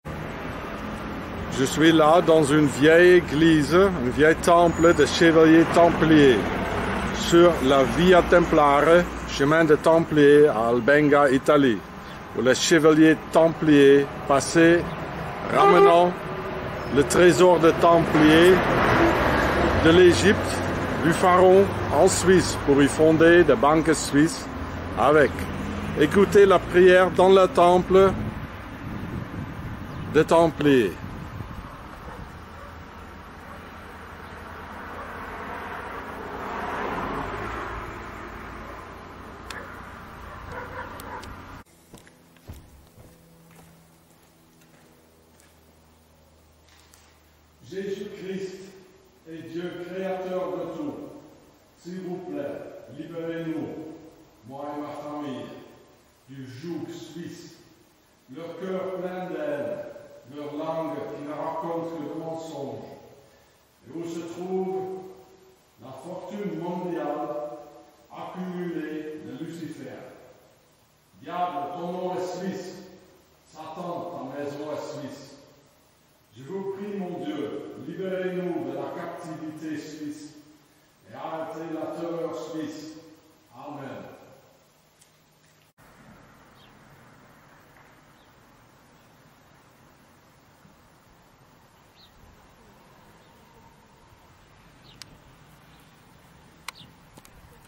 Priere dans l`Eglise des Chevaliers Templiers: ''Liberez moi et ma Famille du Joug Suisse''